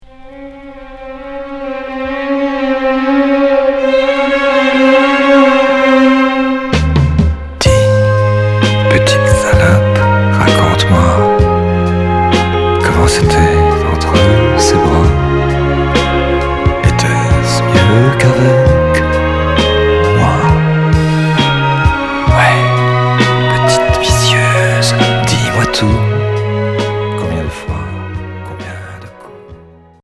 Pop Gainsbourienne